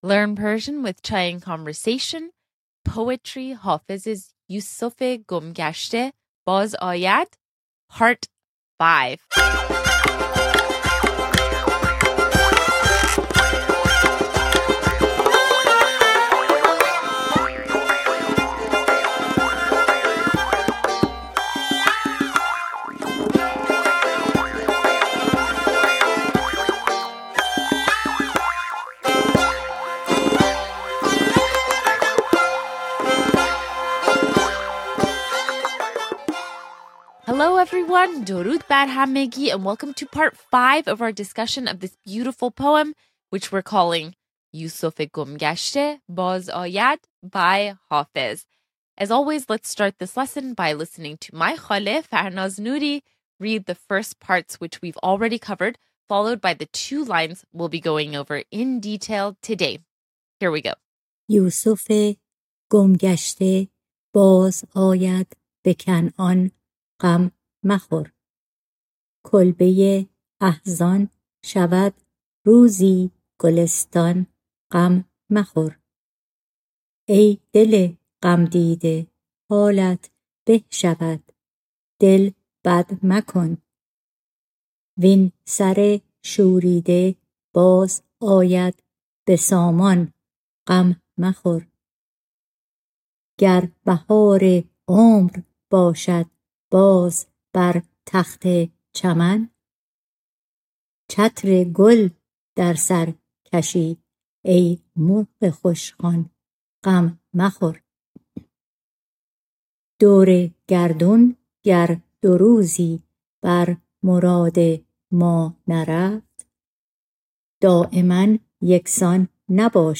In this lesson, we cover the words and phrases in the next four lines of the poem yoosofé gom gashté by Hafez.